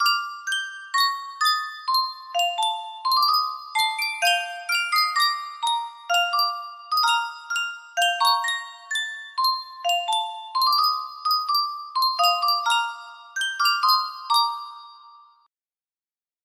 Yunsheng Boite a Musique - Un Clair de Lune 2455 music box melody
Full range 60